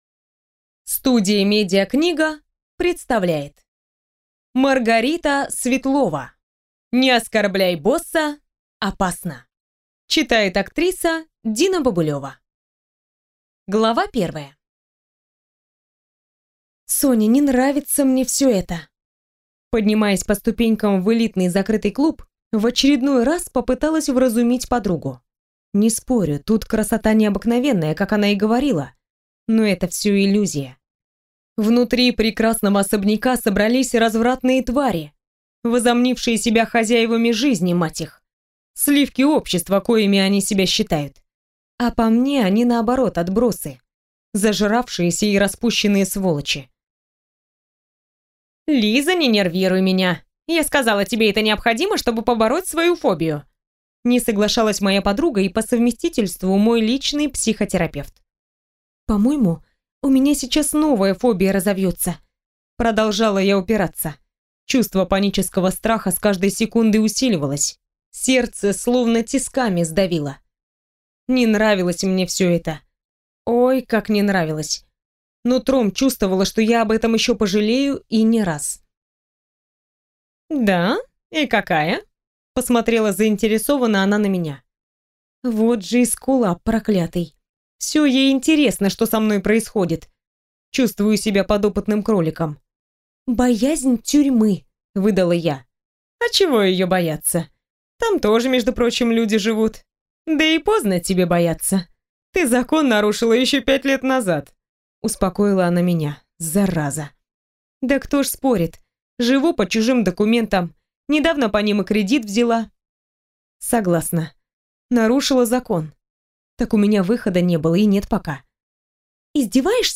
Аудиокнига Не оскорбляй босса, опасно!